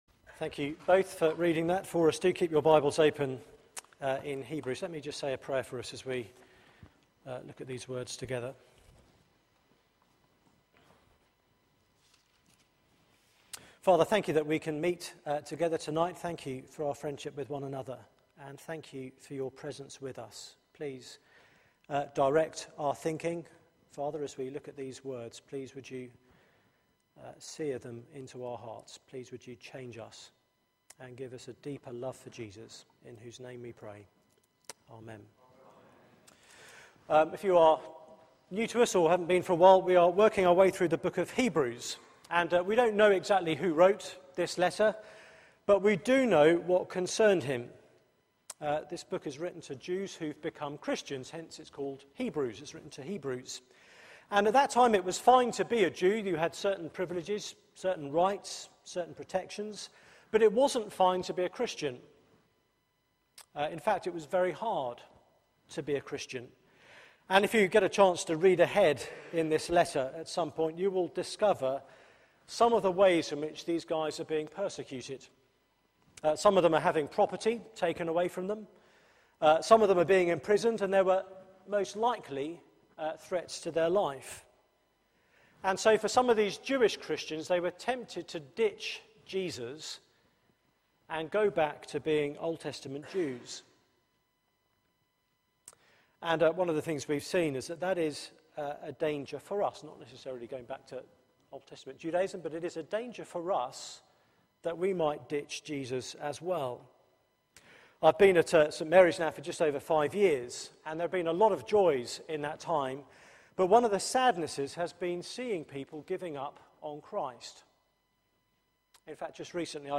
Media for 6:30pm Service on Sun 13th Oct 2013 18:30 Speaker
Theme: One who sympathises Sermon